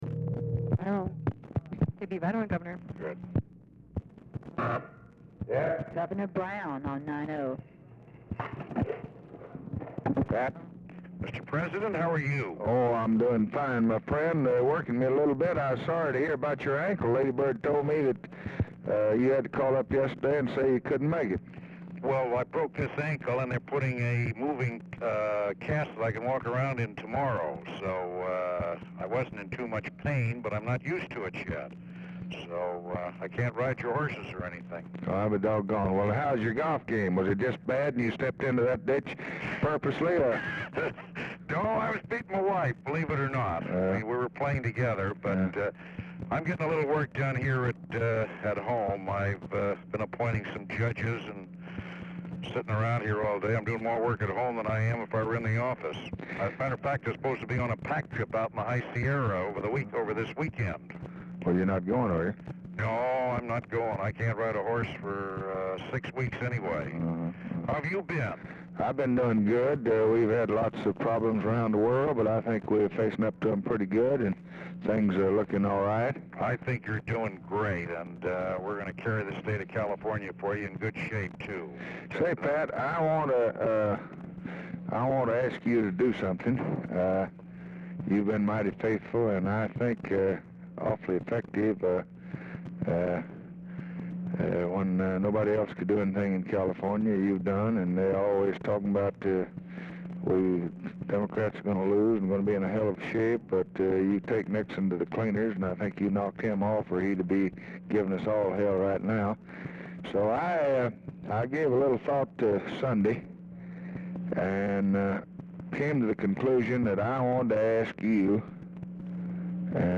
Telephone conversation # 4371, sound recording, LBJ and EDMUND G. "PAT" BROWN, 7/28/1964, 6:01PM | Discover LBJ
Format Dictation belt
Location Of Speaker 1 Oval Office or unknown location
Specific Item Type Telephone conversation